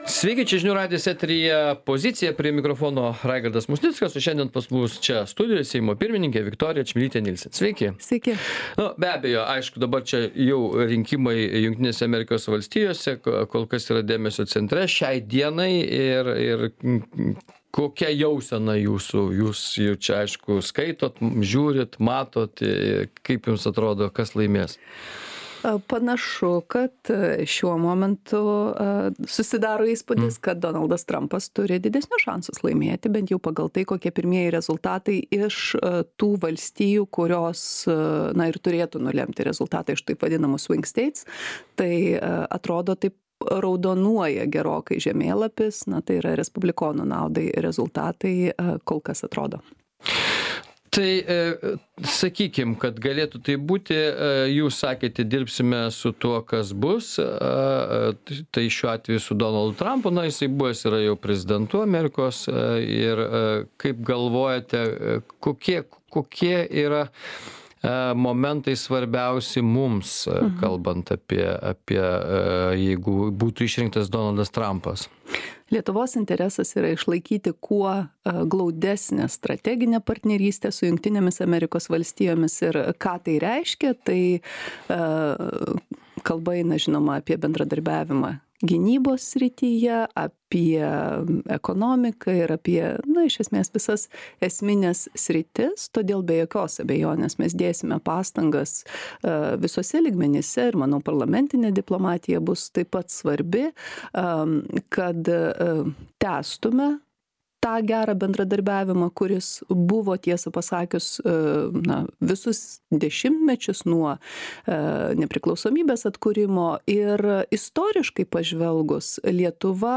Laidoje dalyvauja Seimo pirmininkė Viktorija Čmilytė-Nielsen.